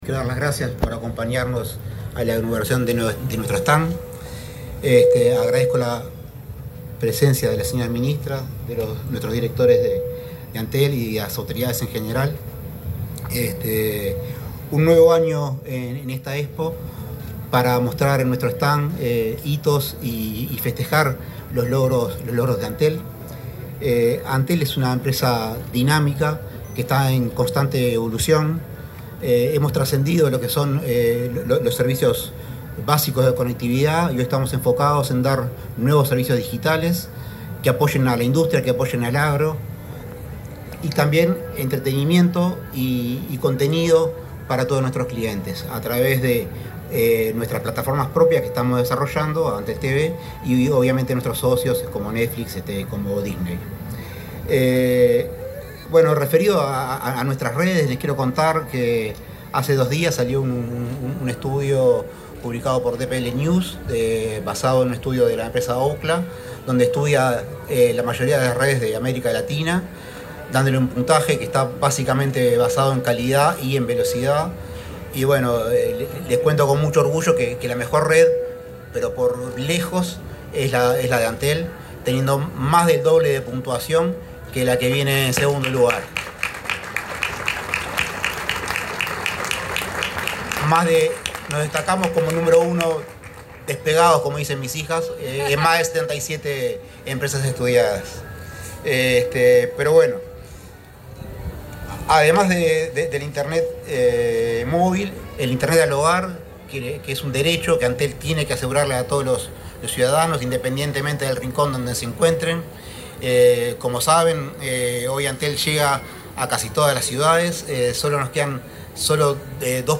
Durante la apertura de un stand de Antel en la Expo Prado 2025, se expresó el presidente de esa empresa estatal, Alejandro Paz.